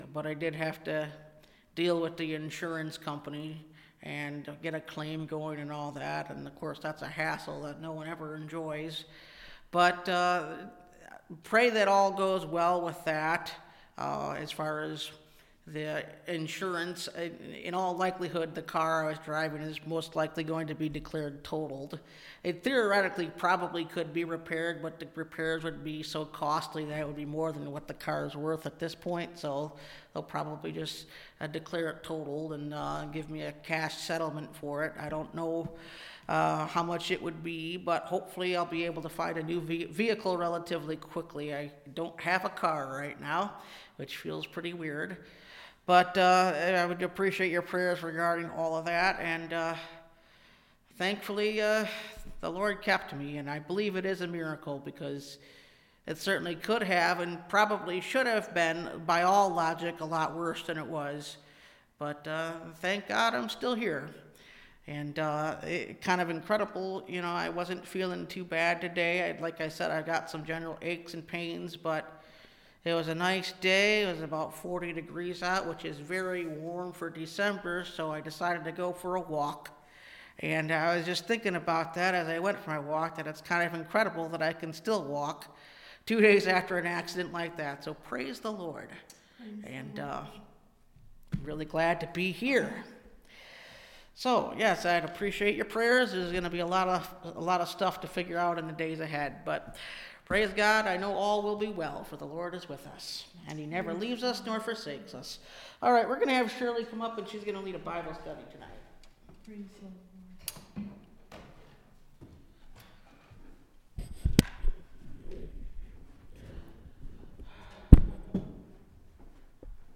Looking For That Blessed Hope (Message Audio) – Last Trumpet Ministries – Truth Tabernacle – Sermon Library